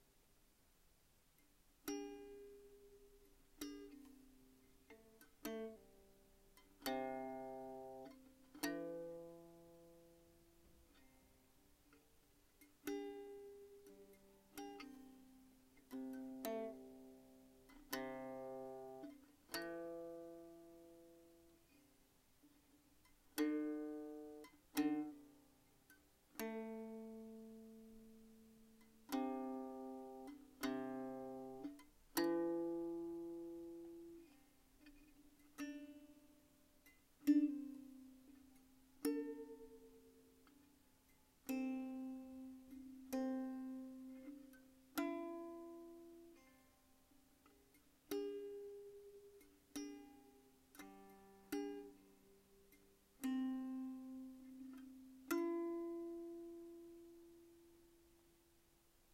三味線